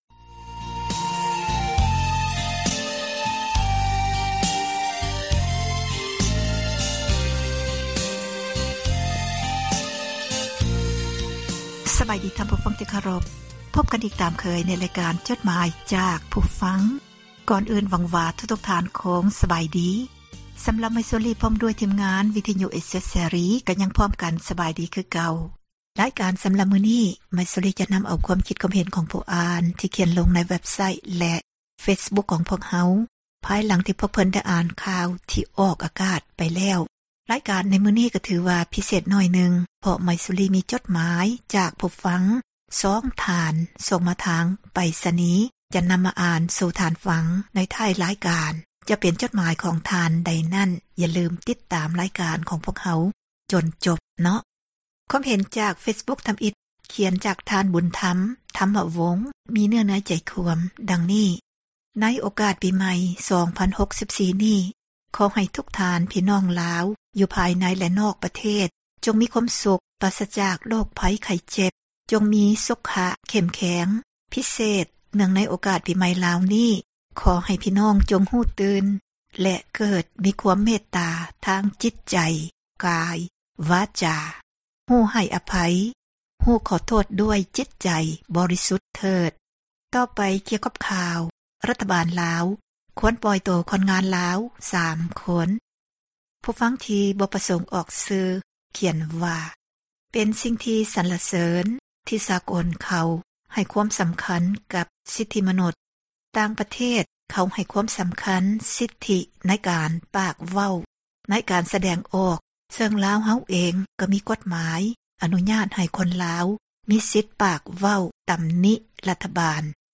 ອ່ານຈົດໝາຍ